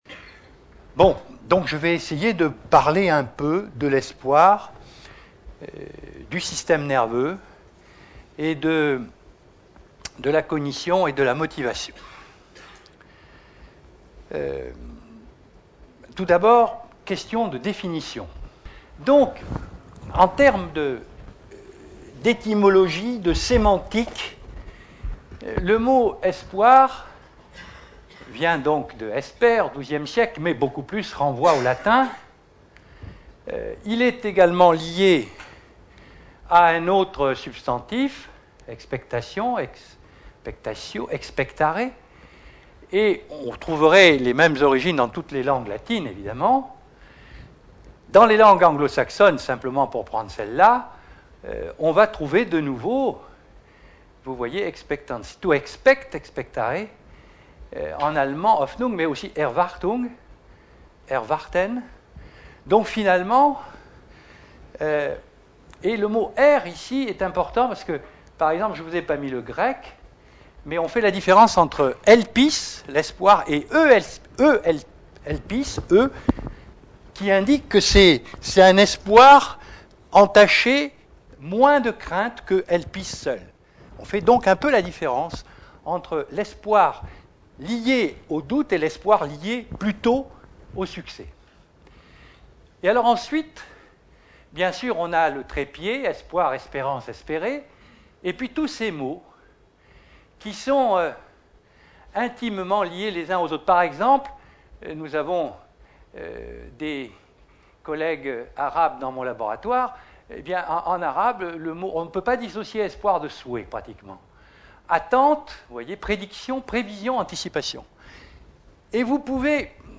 La dopamine est le neurotransmetteur naturel. La conférence a été donnée à l'Université Victor Segalen Bordeaux 2 dans le cadre du cycle de conférences "L'invité du Mercredi" / Saison 2005-2006 sur le thème "L'espoir".